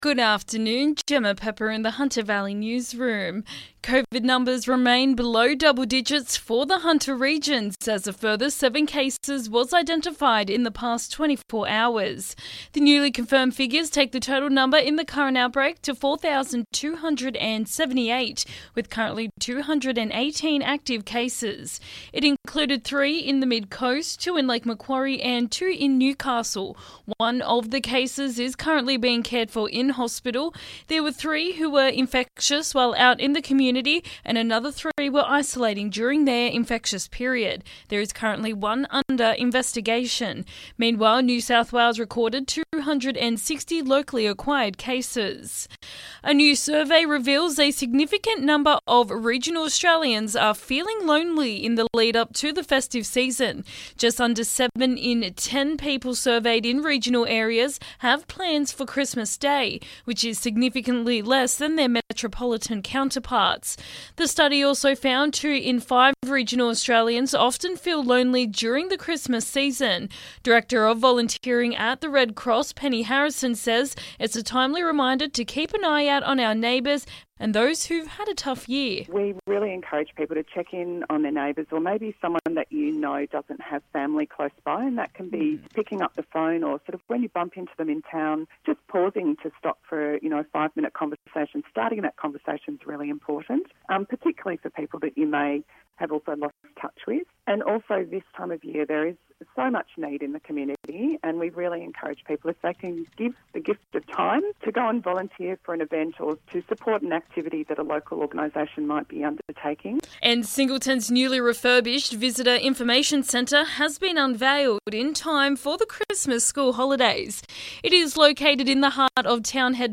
LISTEN: Hunter Valley Local News Headlines 7/12/2021